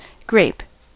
grape: Wikimedia Commons US English Pronunciations
En-us-grape.WAV